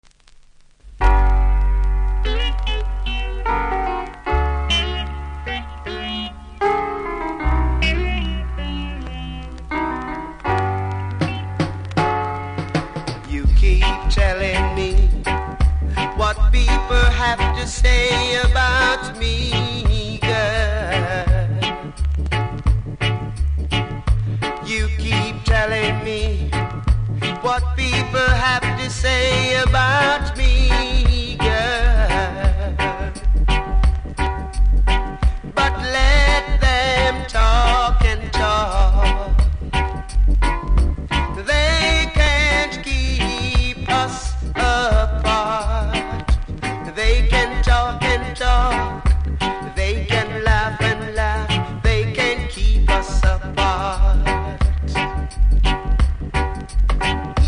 キズは多めですがノイズはそれほどでもなくプレイ可レベルだと思いますので試聴で確認下さい。